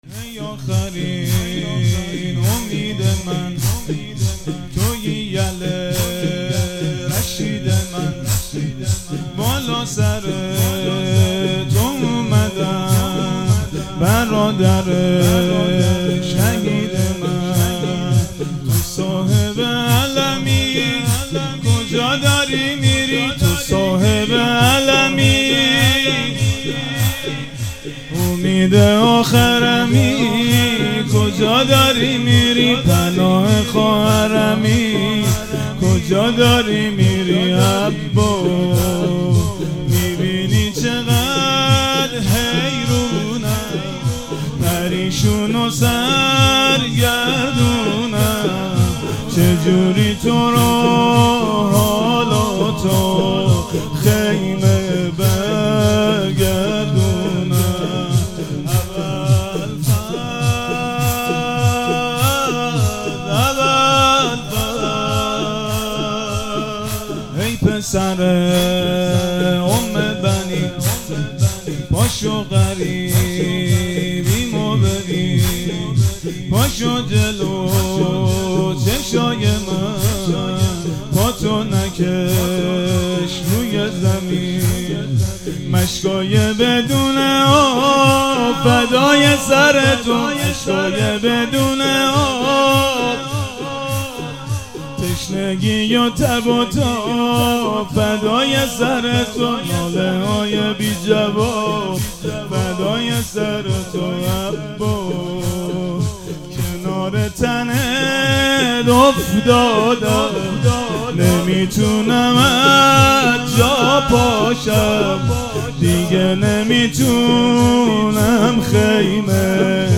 هیئت جاماندگان اربعین کربلا